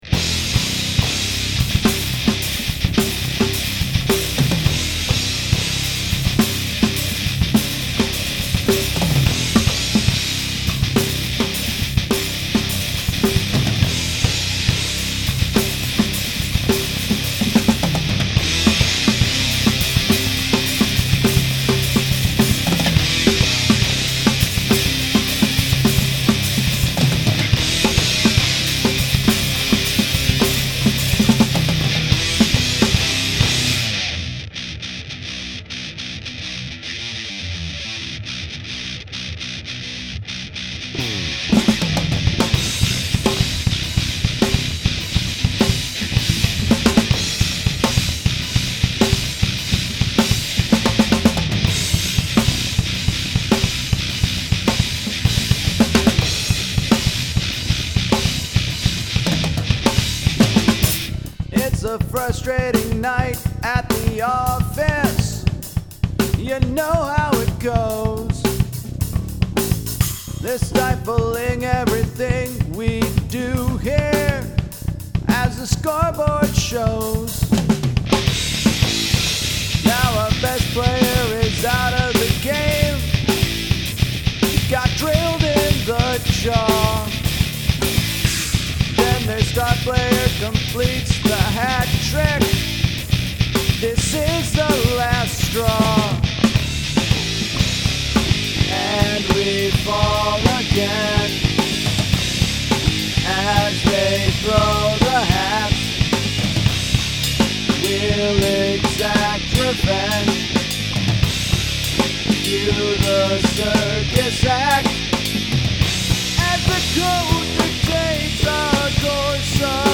I have been a bit out of practice on the drums, (no) thanks to my time in Ohio, so this song was more difficult than I anticipated.
As for everything else: I like off-beats.
But besides that, I wanted this heavy, groovy thing in 4 through two verses and two choruses, then drop all of it and have the rest of the song be completely different.
I like how it starts quiet and builds right up until the end…